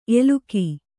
♪ eluki